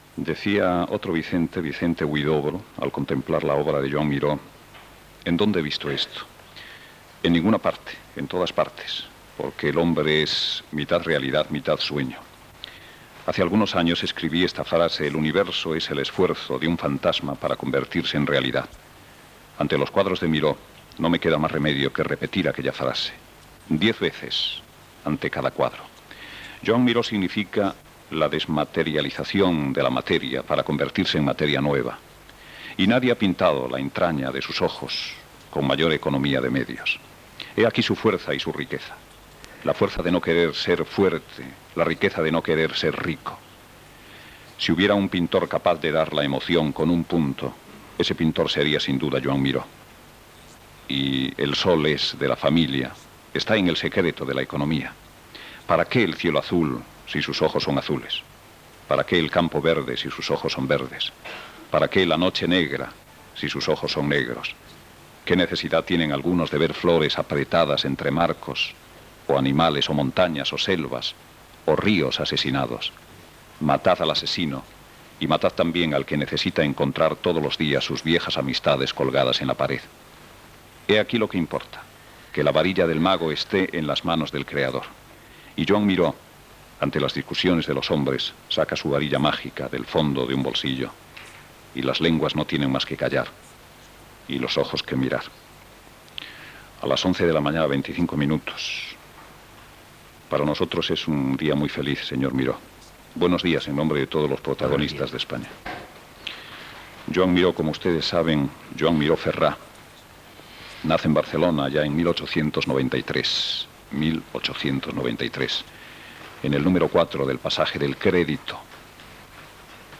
Entrevista al pintor Joan Miró. S'hi parla del pis on va néixer, de la seva mirada del món i de la pintura i de la seva vida personal